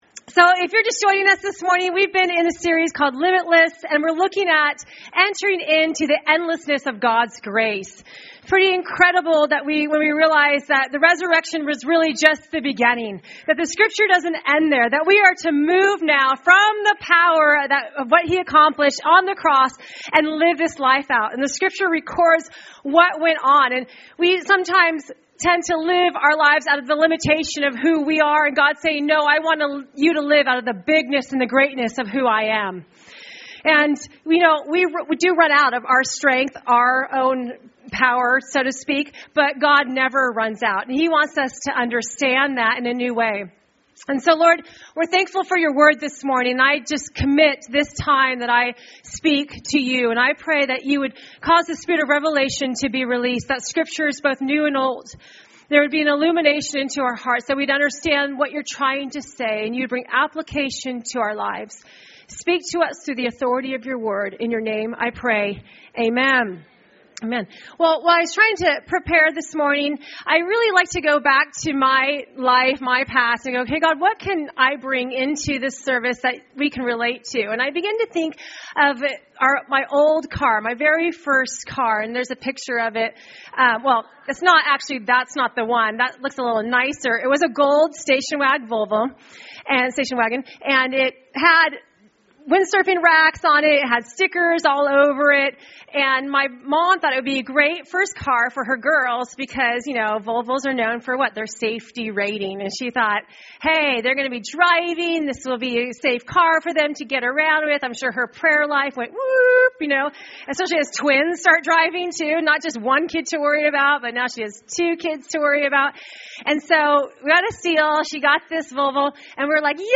Recorded at New Life Christian Center, Sunday, May 31, 2015 at 11AM.
Preaching